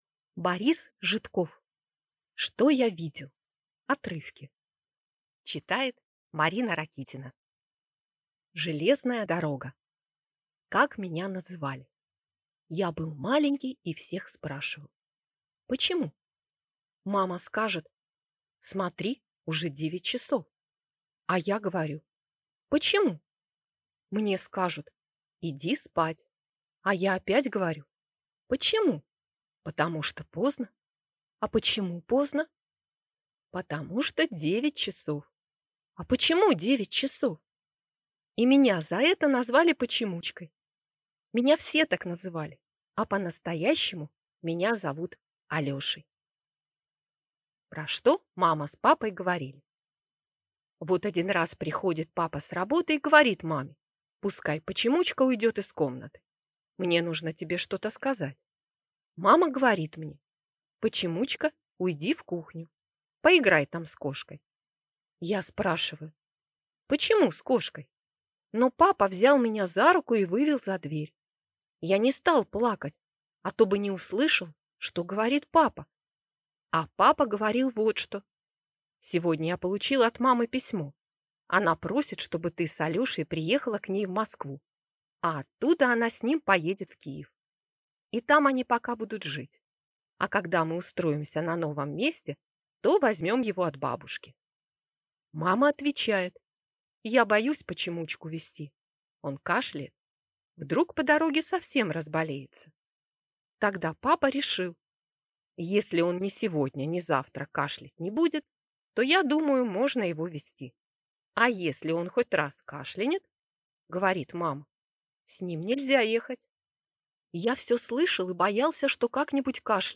Аудиокнига Что я видел (отрывки)